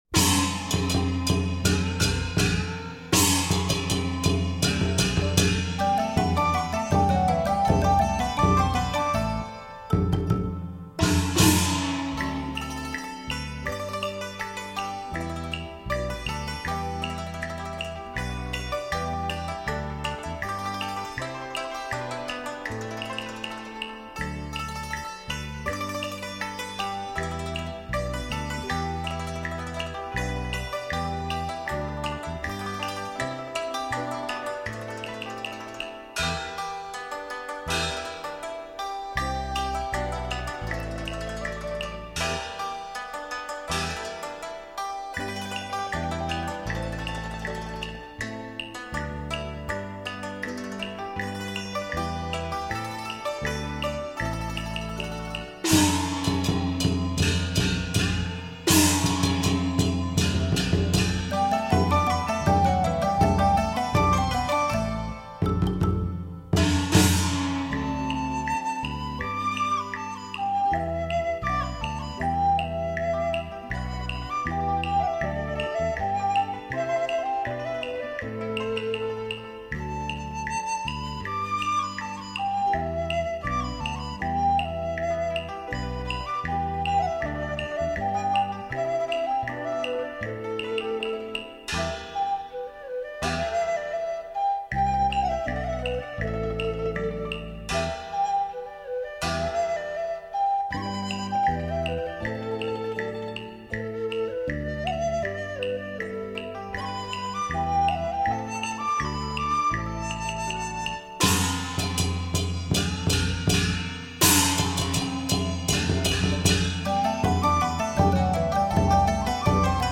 发烧录音
旋律，发烧的录音，此HiFi正碟绝对让乐迷、发烧友、影迷都回味无穷并值得珍藏之。